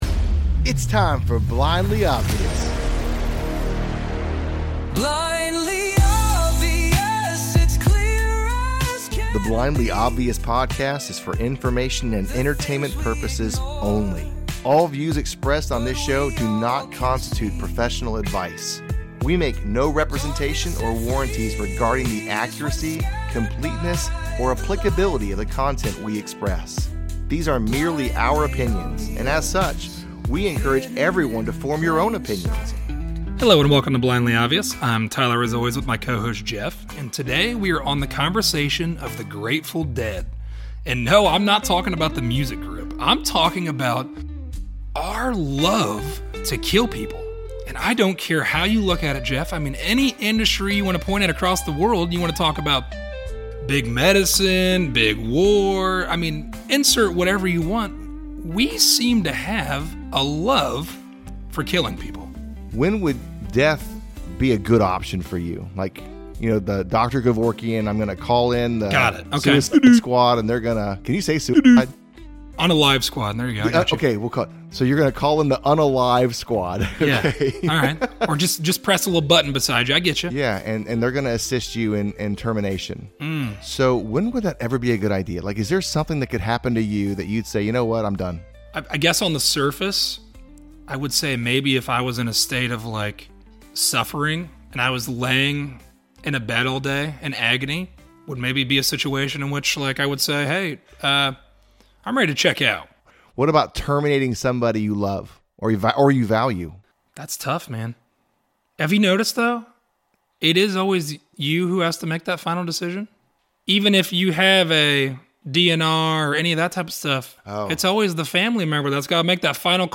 A conversation about the value of all human life.